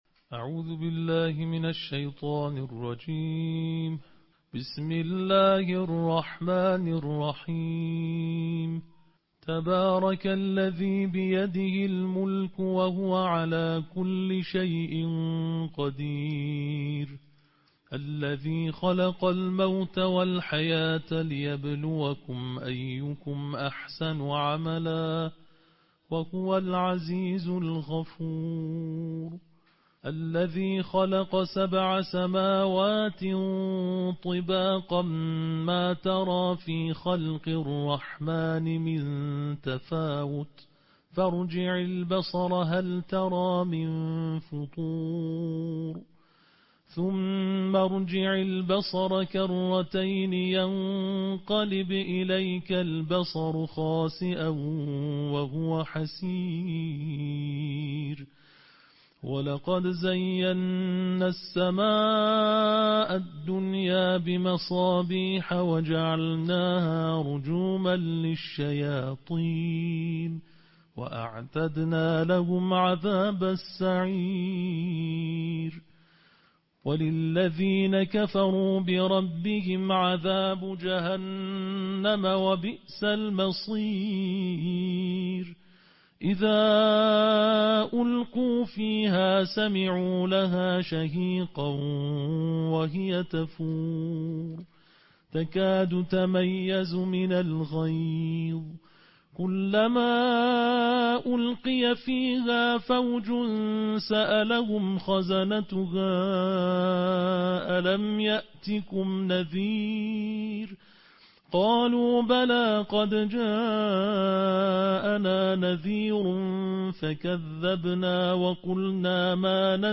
نړیوال قارِيان،د قرآن کریم د نهویشتمې(29) سپارې یا جزوې د ترتیل قرائت